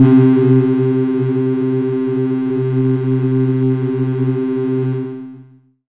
Amb1n2_e_synth_c1_ahhvoice.wav